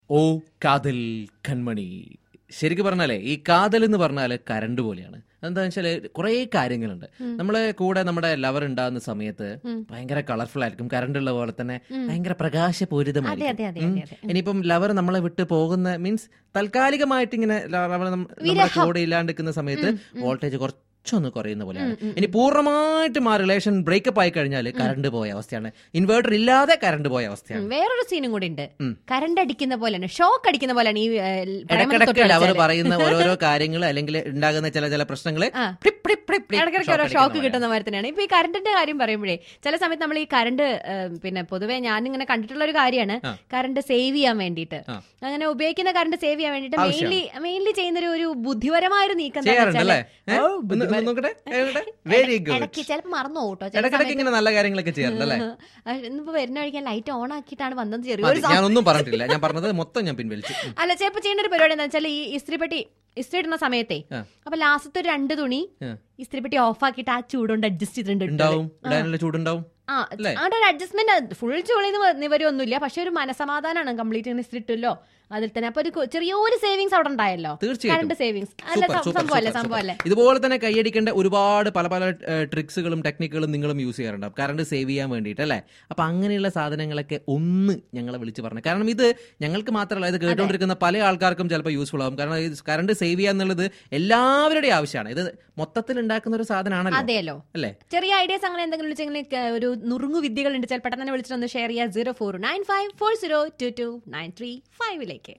FUN TALK.